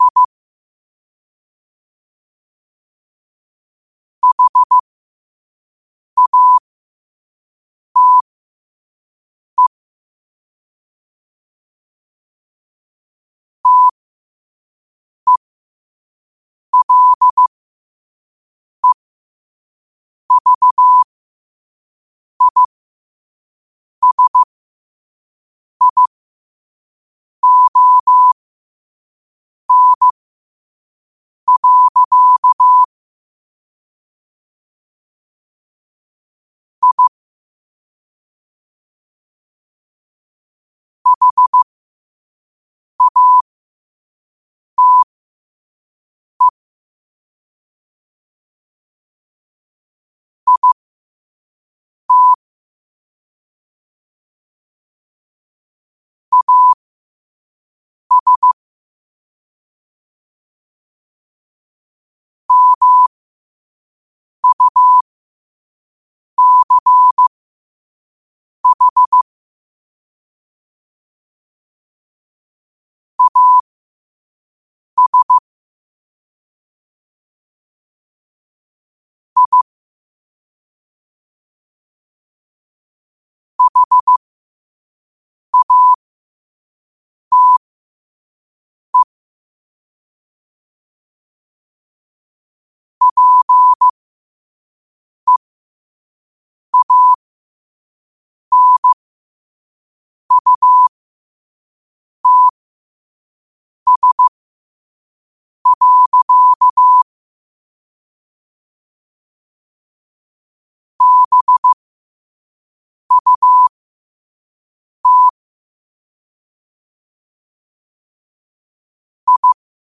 New quotes every day in morse code at 5 Words per minute.